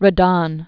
(rə-dŏn, -dôɴ), Odilon 1840-1916.